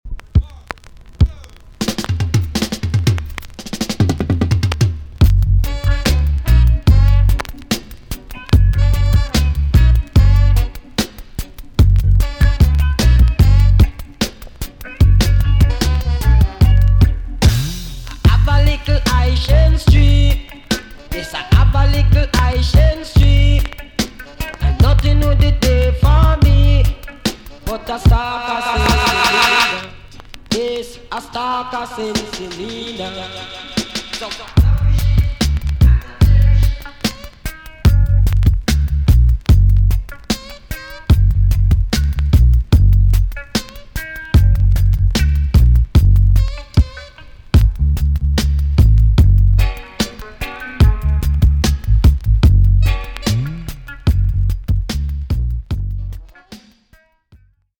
B.SIDE Version
VG+ 少し軽いチリノイズが入ります。